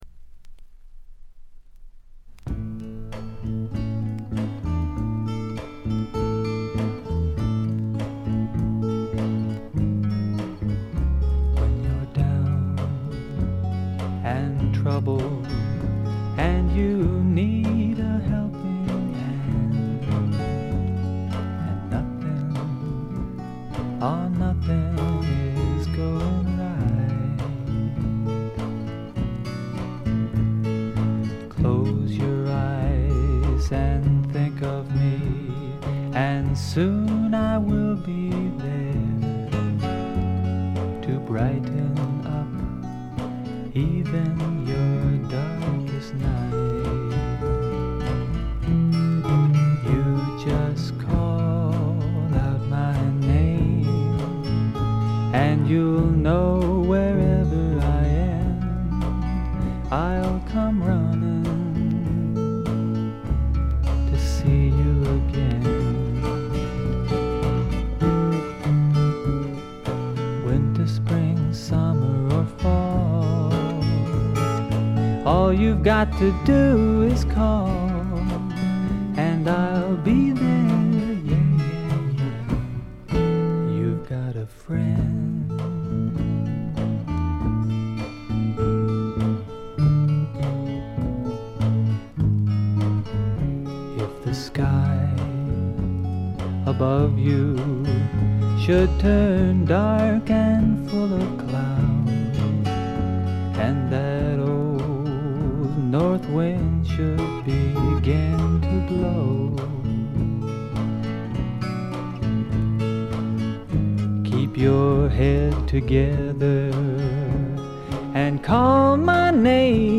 ごくわずかなノイズ感のみ。
マイナーなフォーキー・シンガーソングライター
試聴曲は現品からの取り込み音源です。
Guitar, Vocals Liner Notes